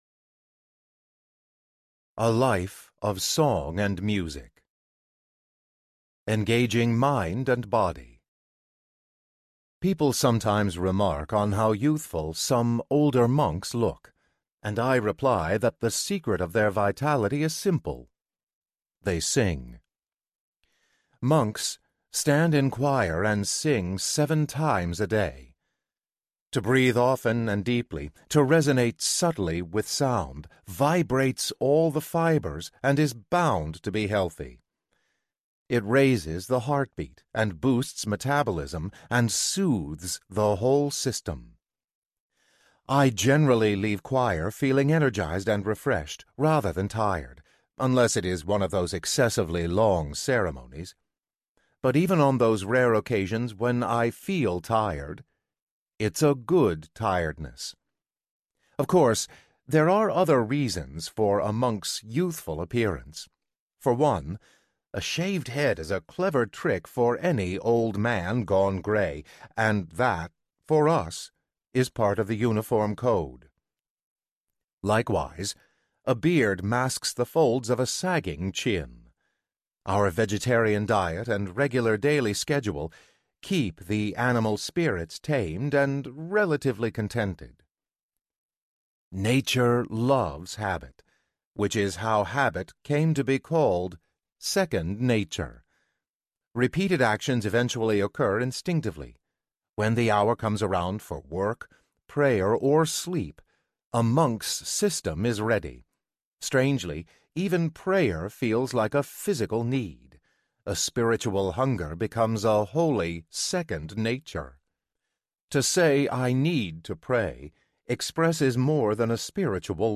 In Praise of the Useless Life Audiobook
Narrator
5.6 Hrs. – Unabridged